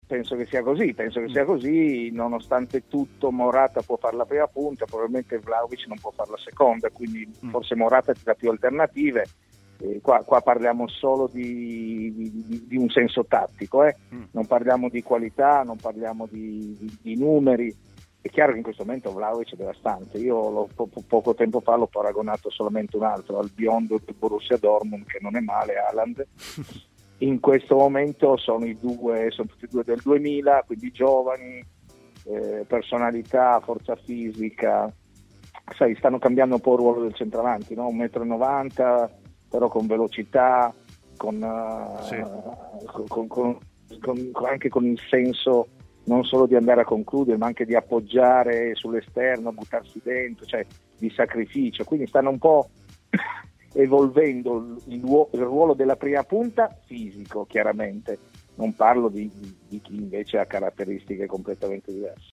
L'ex calciatore Roberto Cravero è intervenuto a Stadio Aperto, trasmissione pomeridiana di TMW Radio, parlando di vari temi, a cominciare dalle differenze tra Vlahovic e Morata.